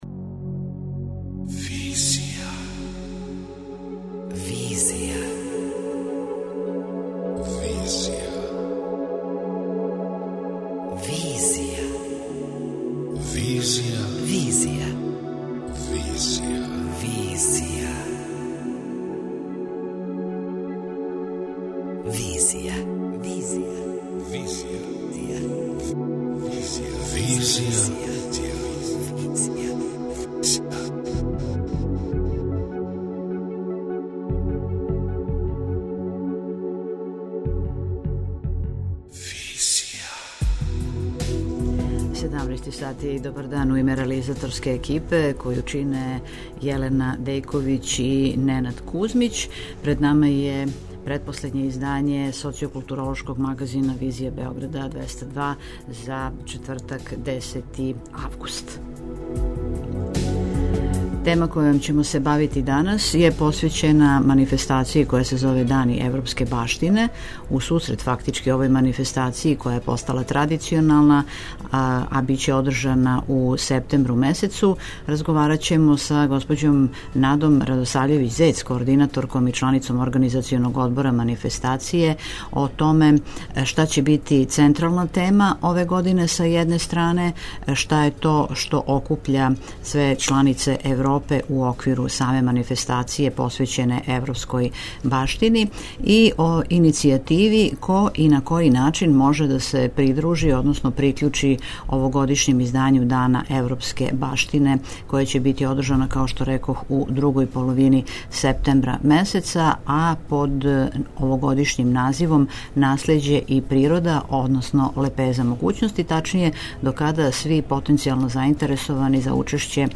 преузми : 28.32 MB Визија Autor: Београд 202 Социо-културолошки магазин, који прати савремене друштвене феномене.